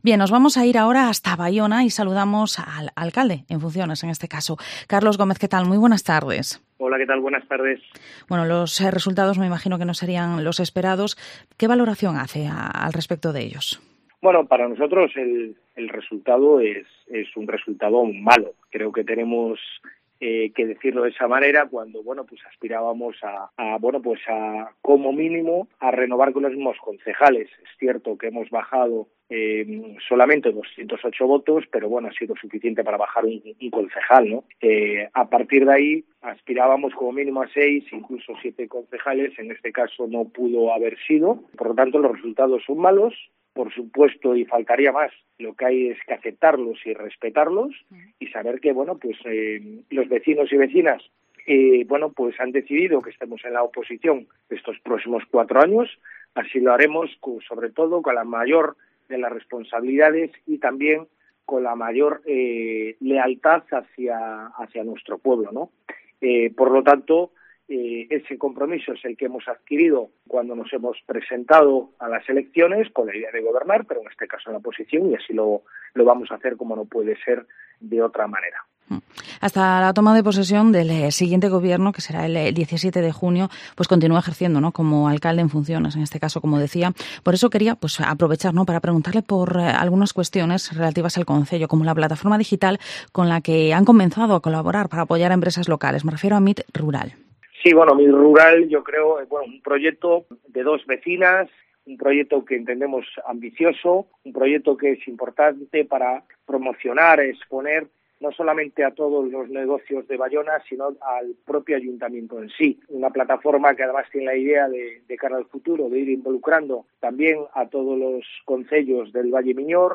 Entrevista al Alcalde en Funciones de Baiona, Carlos Gómez